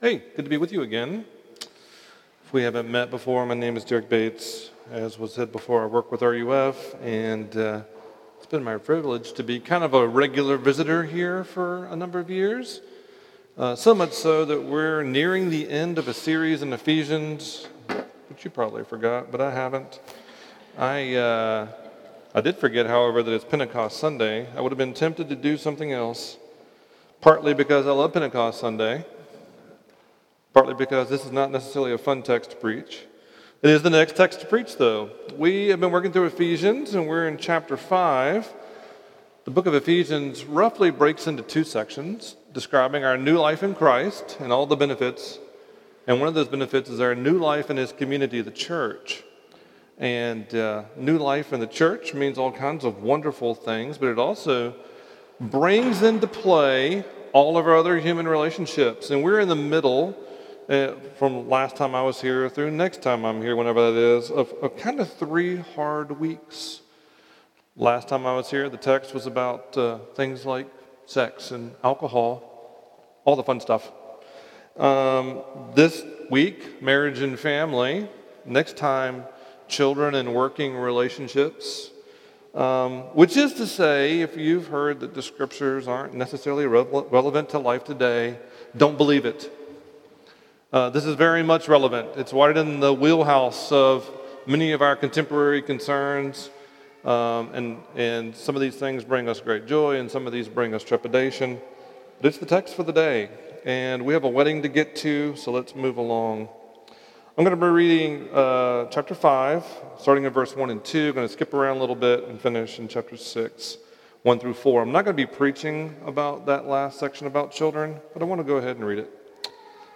Weekly sermons from Grace & Peace PCA in Pittsburgh, PA.